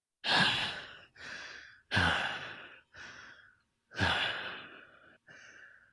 breath0.ogg